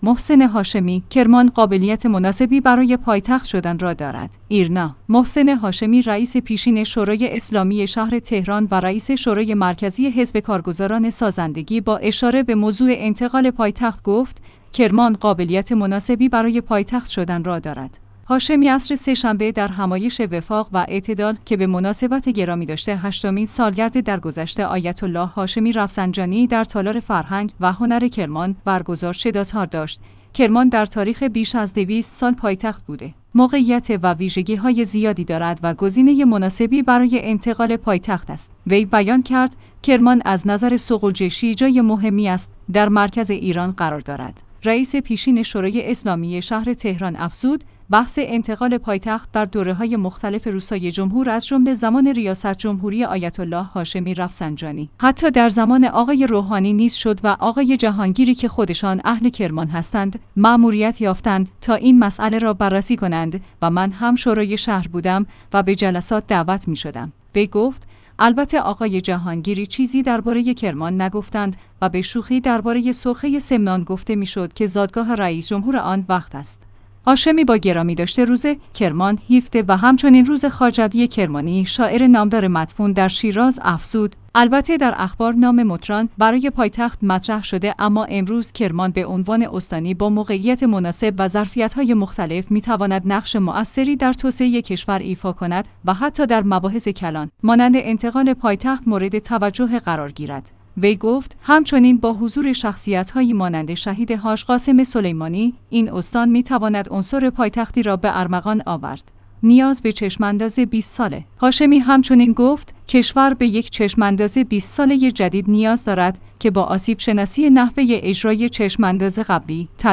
هاشمی عصر سه شنبه در همایش وفاق و اعتدال که به مناسبت گرامیداشت هشتمین سالگرد درگذشت آیت الله هاشمی رفسنجانی در تالار فرهنگ و